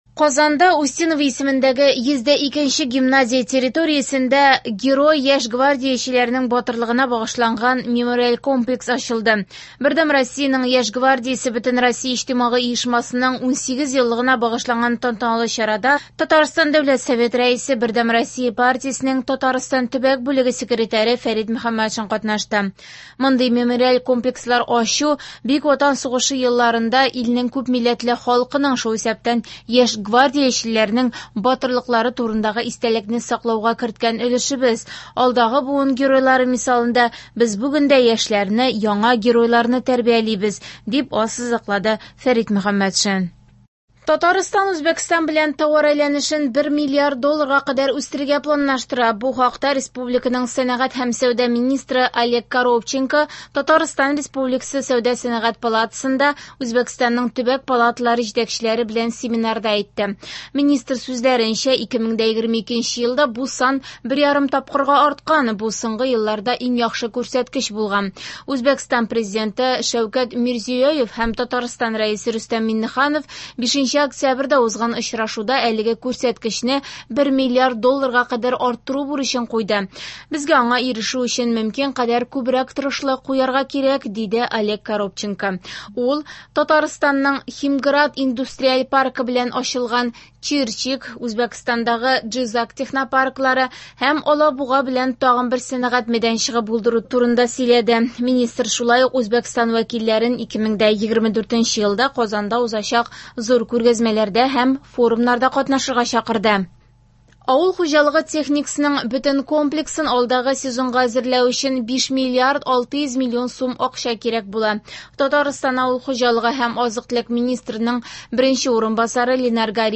Яңалыклар (27.11.23)